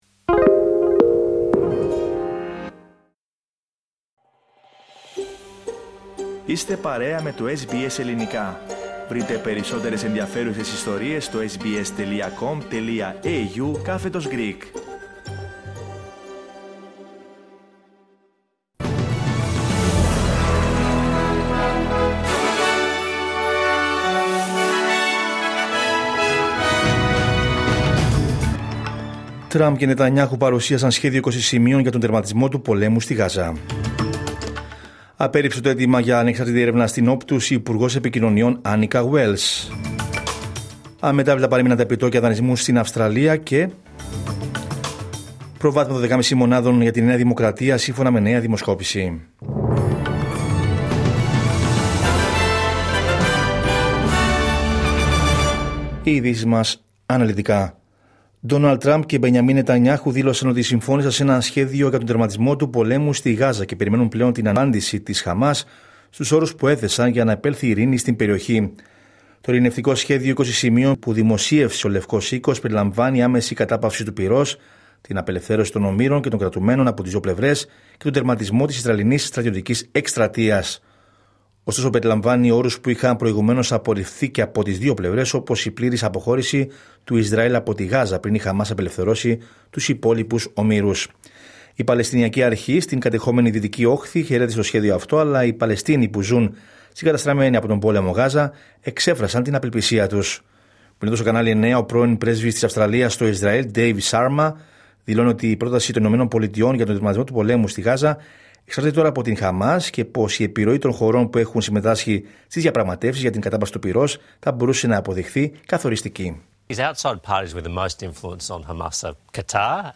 Ειδήσεις από την Αυστραλία, την Ελλάδα, την Κύπρο και τον κόσμο στο Δελτίο Ειδήσεων της Τρίτης 30 Σεπτεμβρίου 2025.